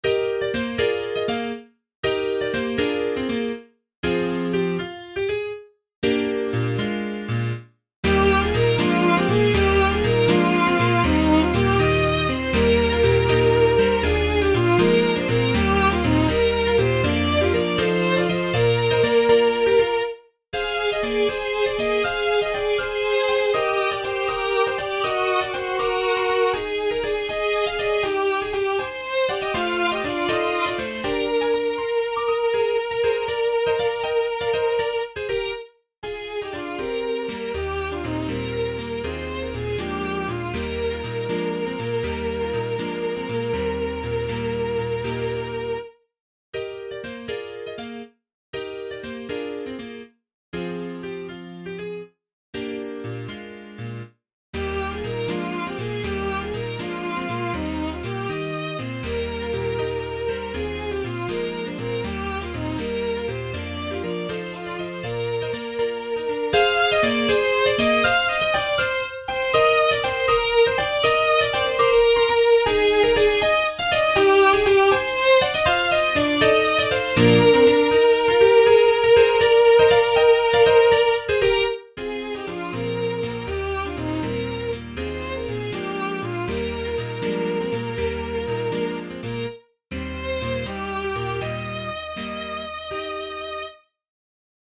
Recordings are synthesized, so they have some wrong timings, very limited phrasing and dynamics, and had to substitute violin soundfont for original vocal line just to get a rough idea of the flow.
Click here to play or download an mp3 audio recording of “Lullaby Land” (will sound mechanical, as this is merely scanned from old sheet music, then vocal line assigned to violin soundfont)
Lullaby_Land__violin.mp3